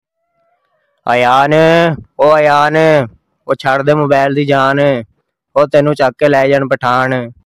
Goat 🐐 Calling Funny Name Sound Effects Free Download